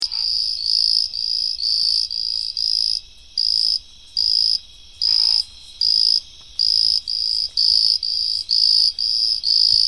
Males call from blades of tall grass or shrubs that are emergent in the water.
Call is shrill, insect like buzz, sometimes likened to a cricket.